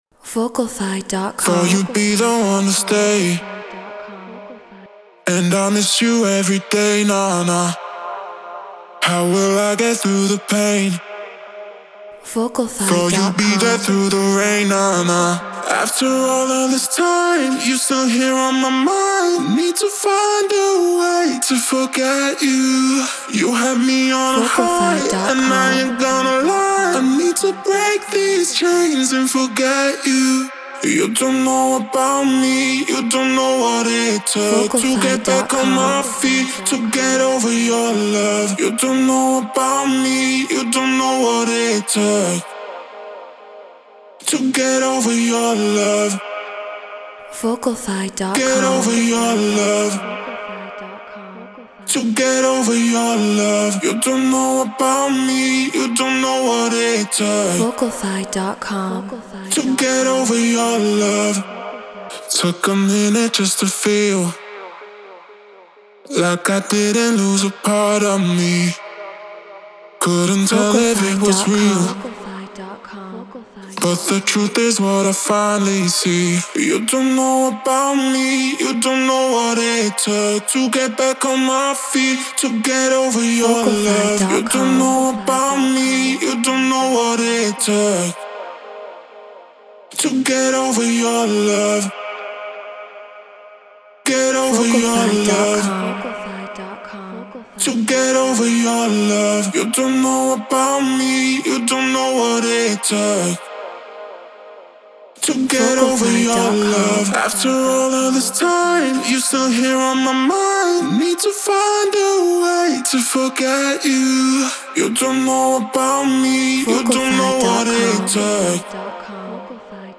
House 126 BPM Fmin
Neumann TLM 103 Focusrite Scarlett Pro Tools Treated Room